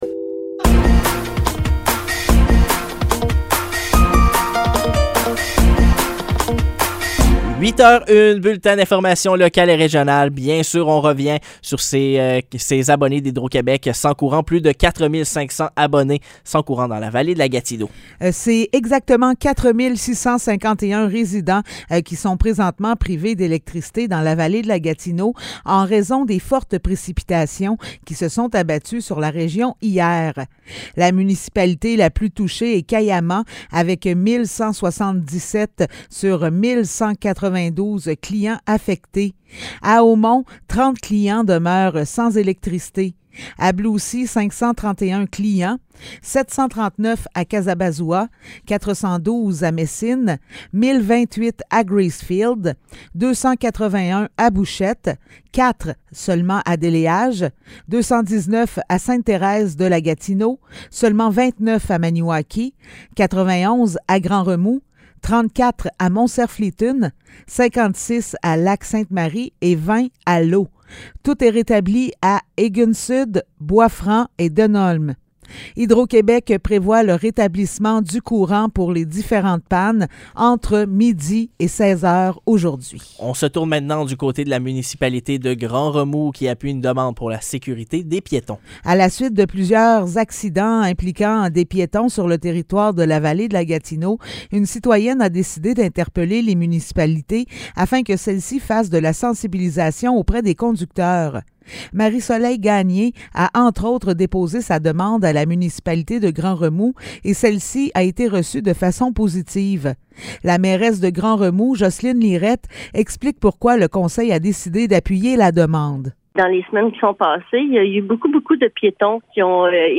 Nouvelles locales - 12 juillet 2023 - 8 h